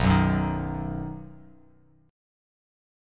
B Chord
Listen to B strummed